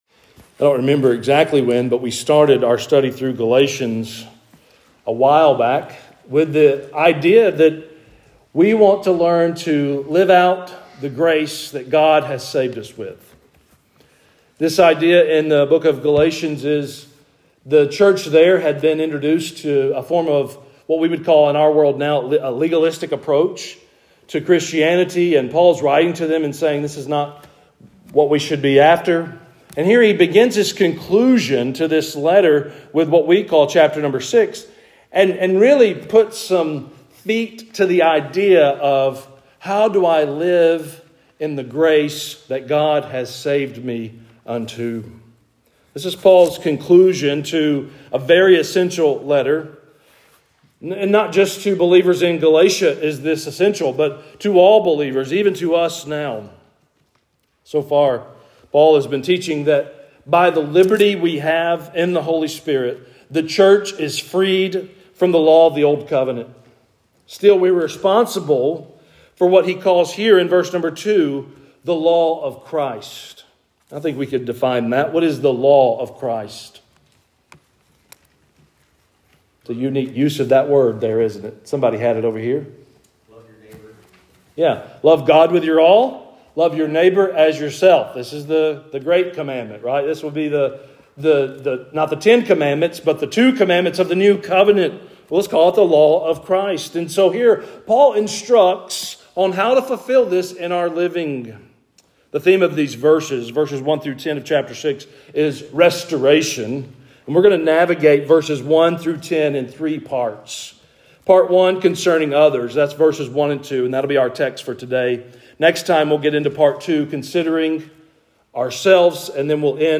Sermons | Harpeth Baptist Church